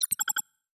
Holographic UI Sounds 111.wav